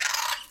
sounds_parrot_03.ogg